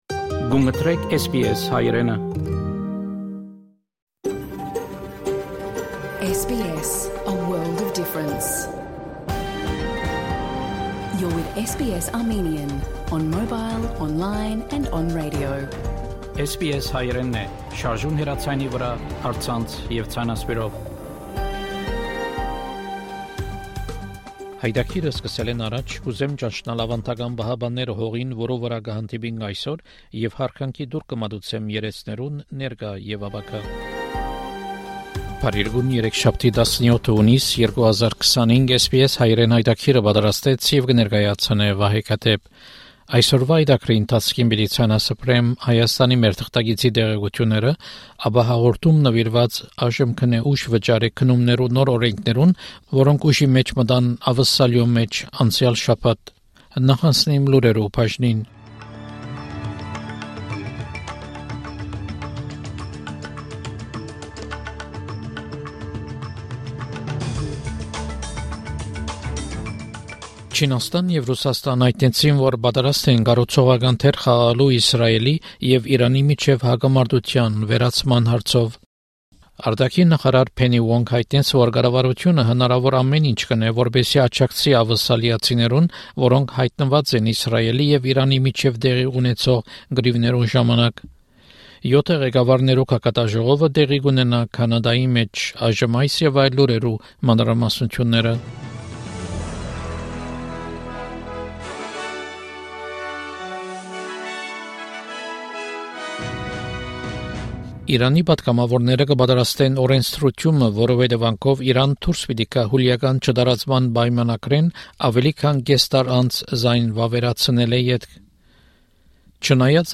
SBS Armenian news bulletin from 17 June 2025 program.